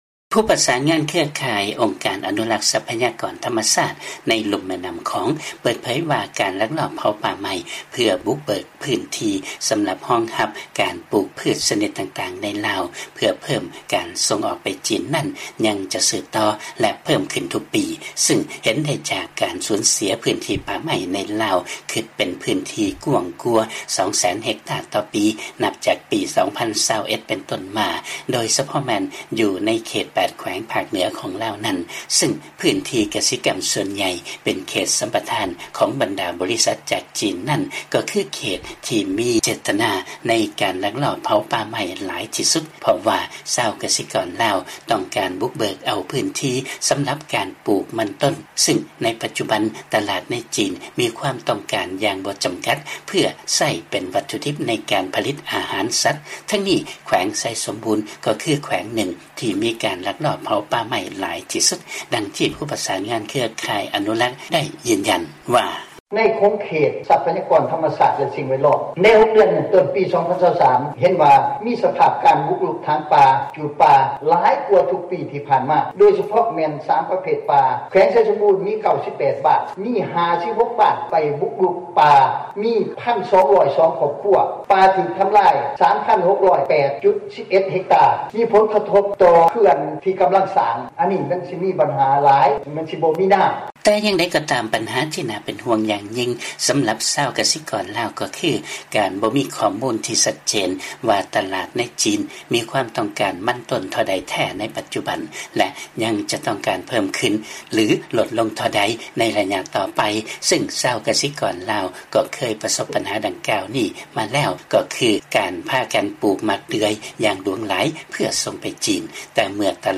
ມີລາຍງານເລື້ອງນີ້ຈາກບາງກອກ.